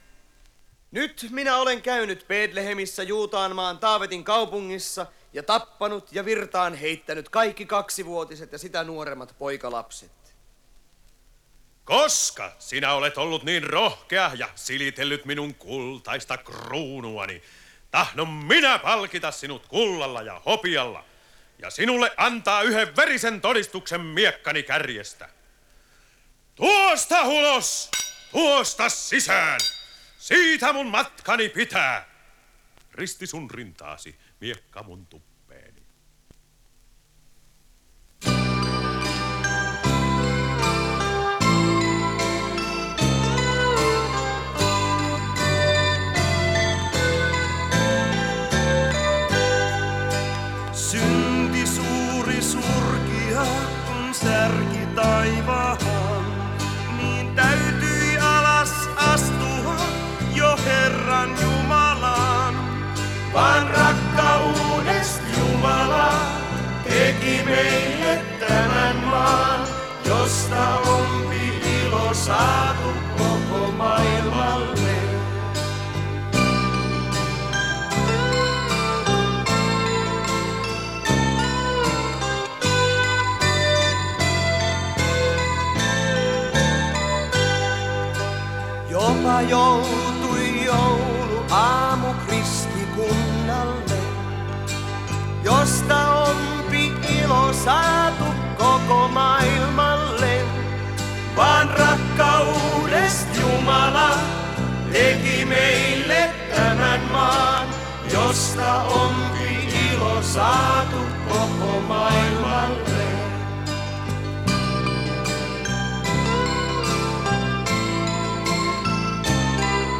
Hän myös soitti kosketinsoittimet.